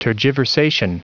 Prononciation du mot tergiversation en anglais (fichier audio)
Prononciation du mot : tergiversation